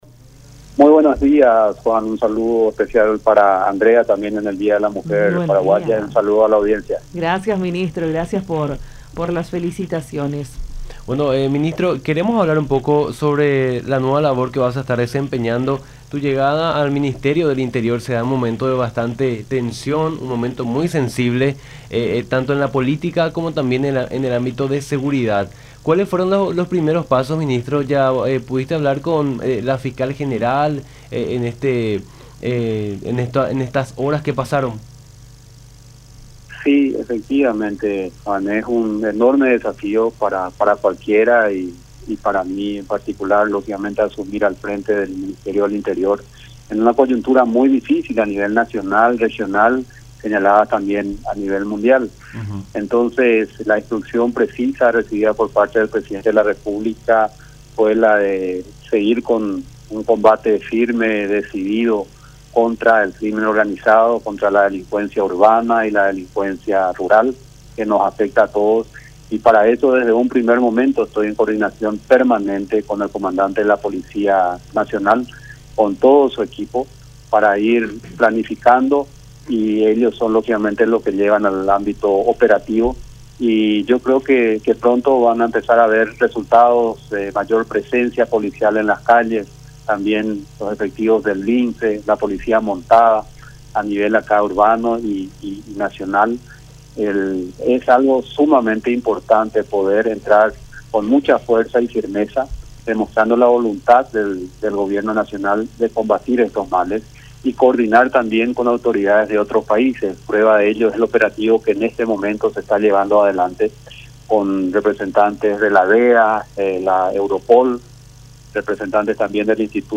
“Es un enorme desafío el que estoy asumiendo, y la instrucción precisa es seguir con el combate contra el crimen organizado y otro tipo de crímenes que se dan en nuestro país”, afirmó González en conversación con Nuestra Mañana por La Unión.